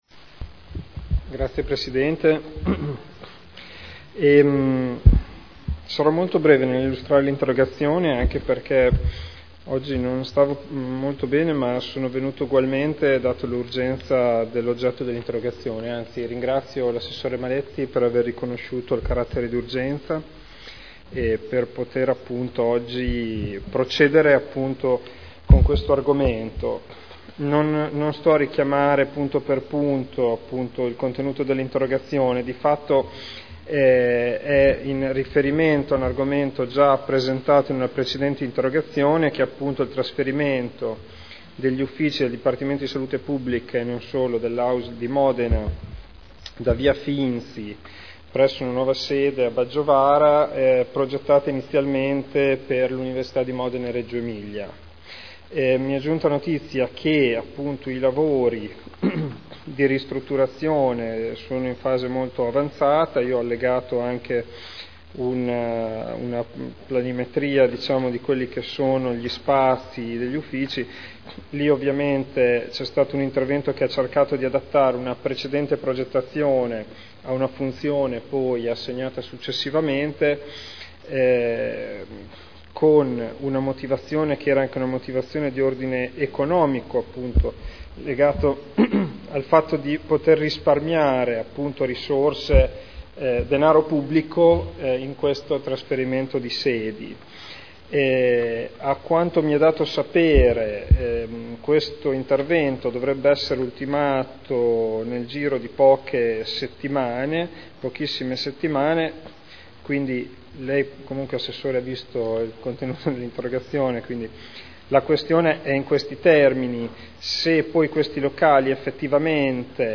Seduta del 19/09/2011. Interrogazione urgente del consigliere Ricci (Sinistra per Modena) avente per oggetto: “Nuova sede DSP AUSL MO”